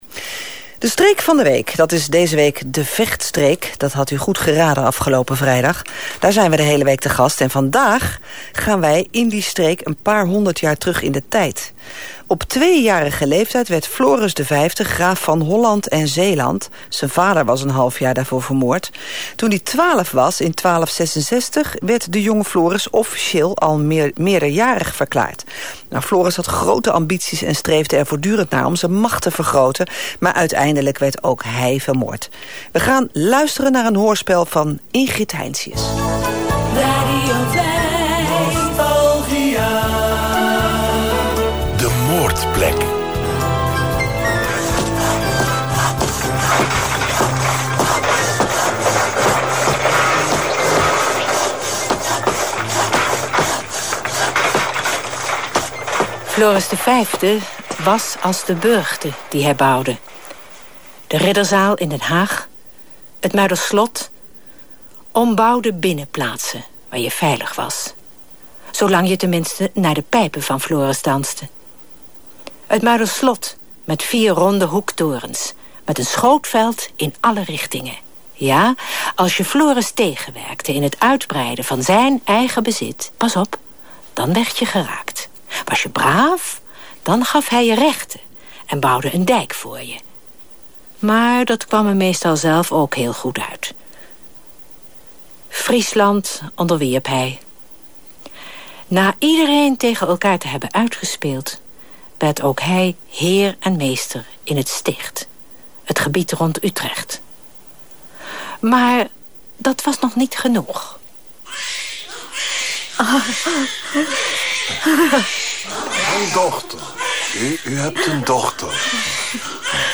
In elke aflevering wordt in een kort hoorspel de moord op een historische figuur beschreven